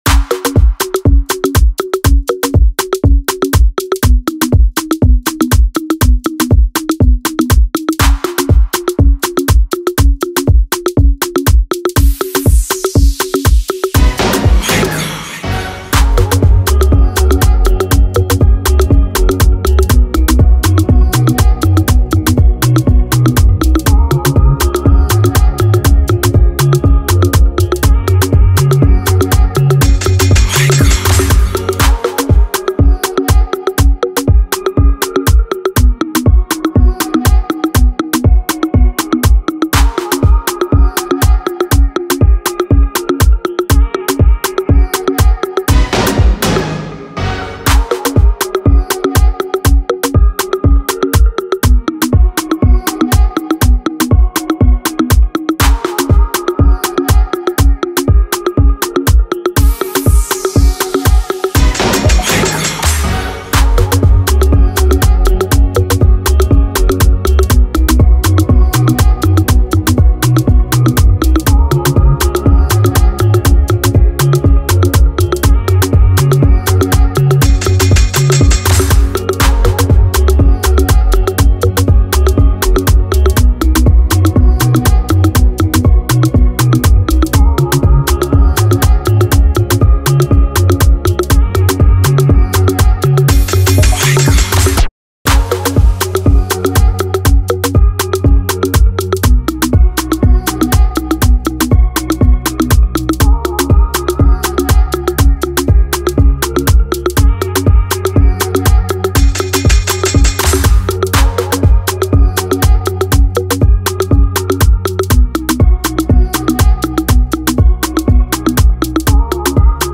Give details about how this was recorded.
instrumental remake